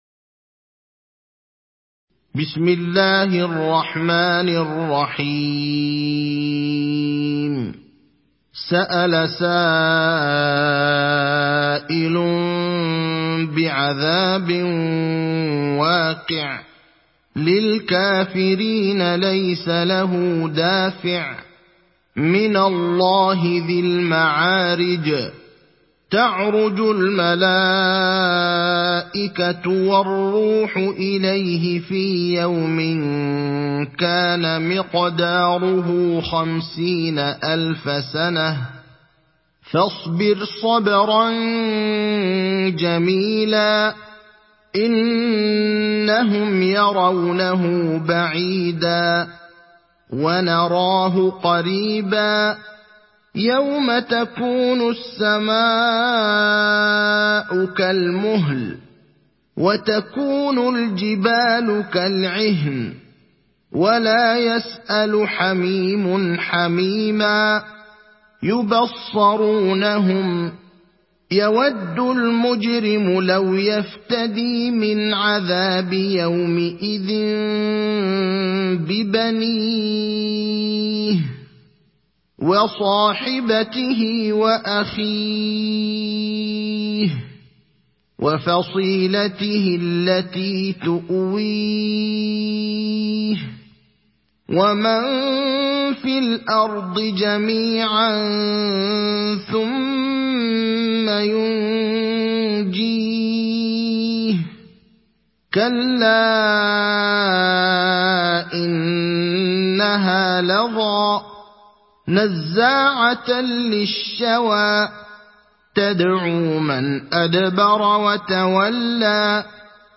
Mearic Suresi İndir mp3 Ibrahim Al Akhdar Riwayat Hafs an Asim, Kurani indirin ve mp3 tam doğrudan bağlantılar dinle